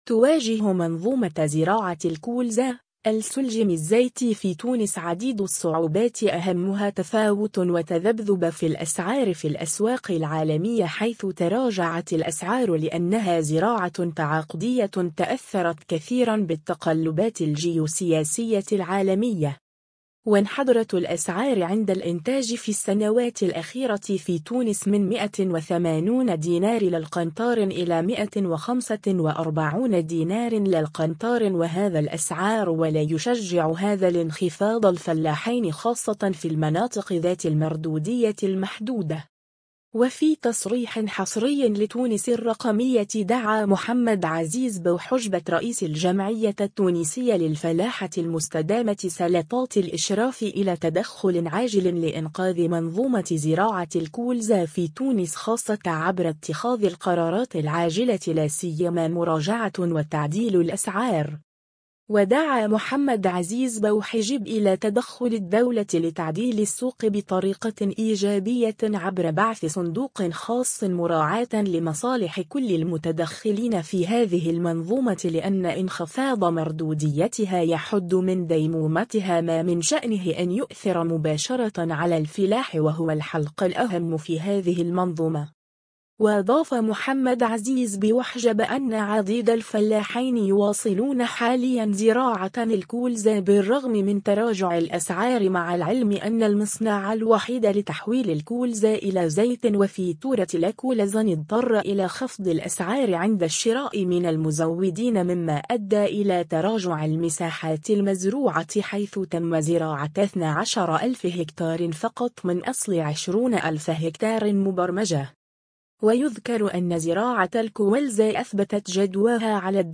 تصريح
وفي تصريح حصري لتونس الرقمية